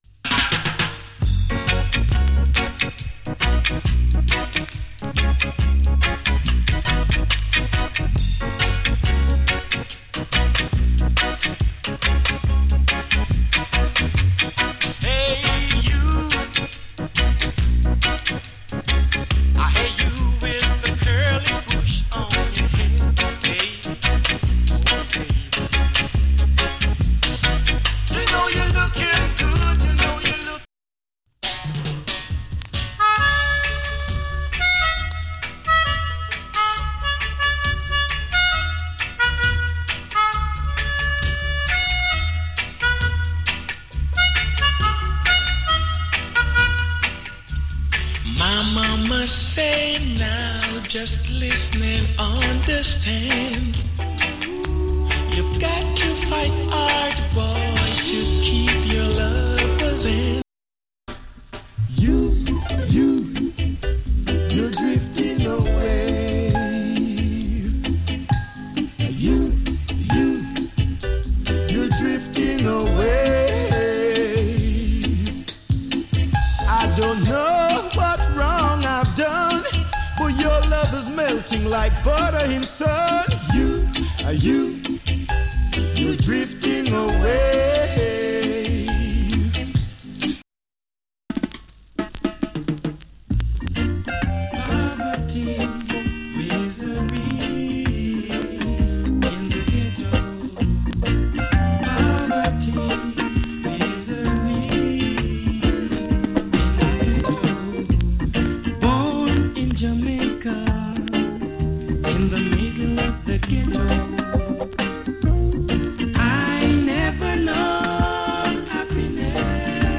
プレスによるノイズ少し有り。